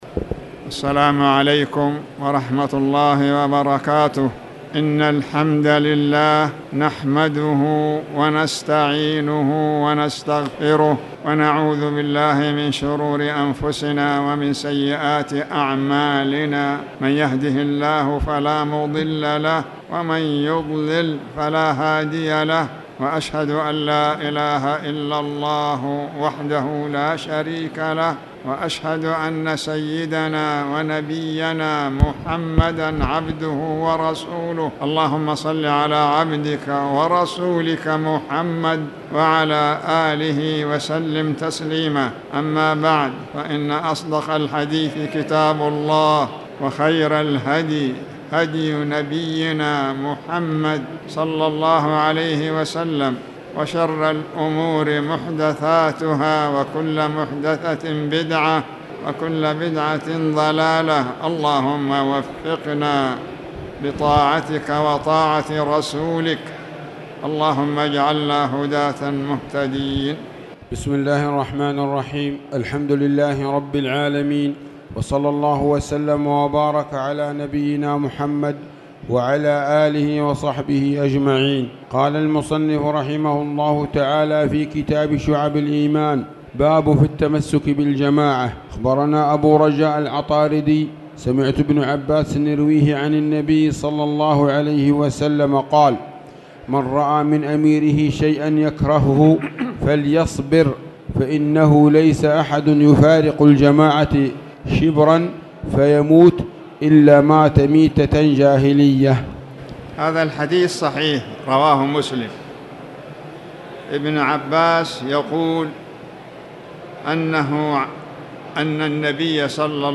تاريخ النشر ٧ ربيع الأول ١٤٣٨ هـ المكان: المسجد الحرام الشيخ